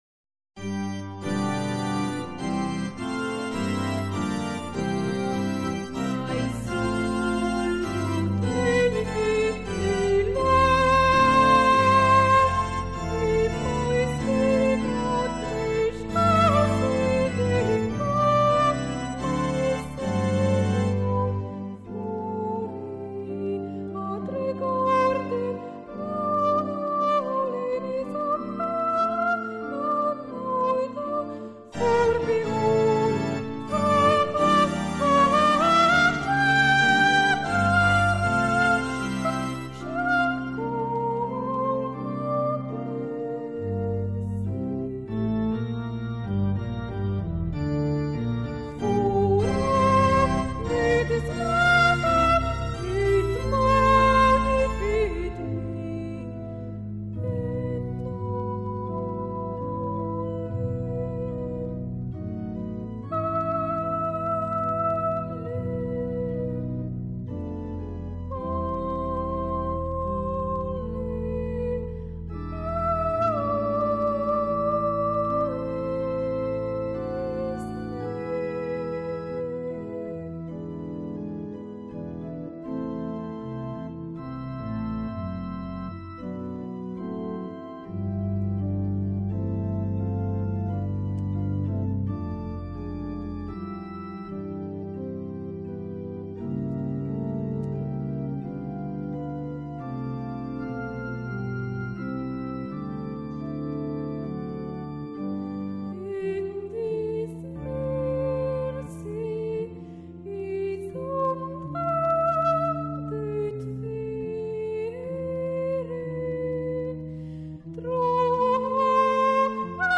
Parti per i soprani primi
MAGNIFICAT BLAIR (soprani I)